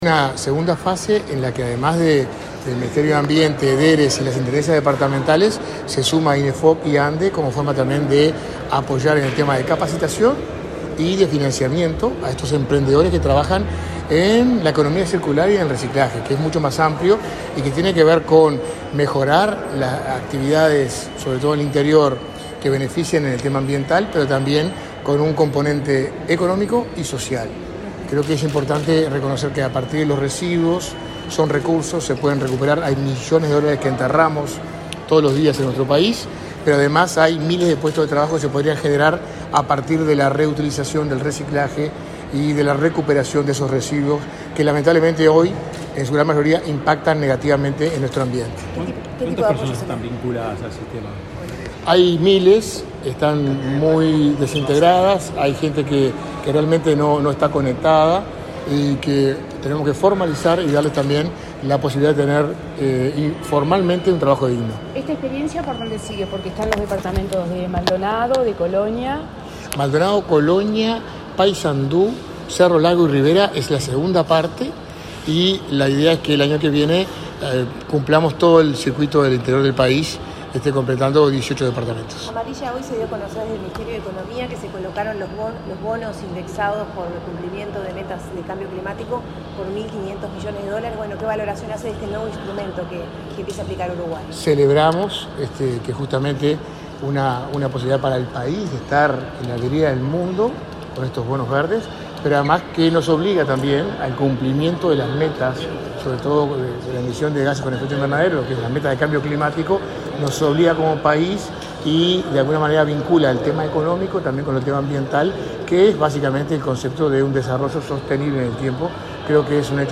Declaraciones a la prensa del subsecretario de Ambiente
Declaraciones a la prensa del subsecretario de Ambiente 21/10/2022 Compartir Facebook X Copiar enlace WhatsApp LinkedIn Este viernes 21 en la Torre Ejecutiva, el subsecretario de Ambiente, Gerardo Amarilla, dialogó con la prensa, luego de participar en la firma de un nuevo acuerdo de cooperación para continuar con el apoyo a emprendimiento de economía circular en el interior del país.